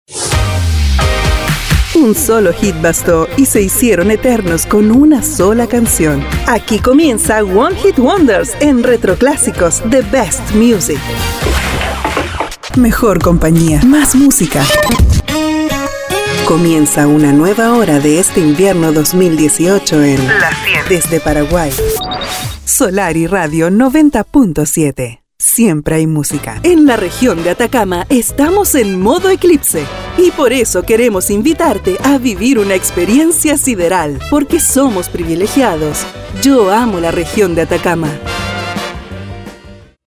Friendly
Smooth
Sexy